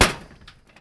pl_grate4.wav